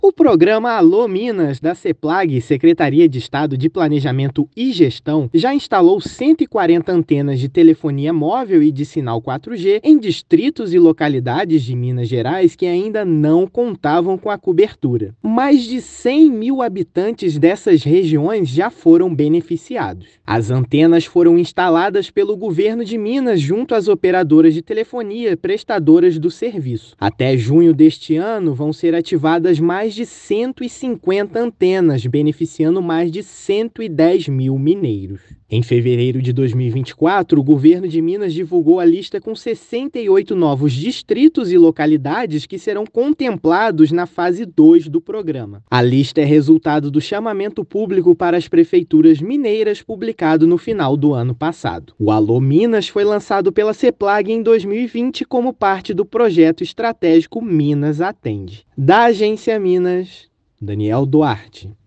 [RÁDIO] Alô, Minas! beneficia mais de 100 mil mineiros com cobertura de telefonia móvel e internet
Programa da Seplag-MG instalou antenas em 140 localidades que não tinham acesso aos serviços. Ouça a matéria de rádio: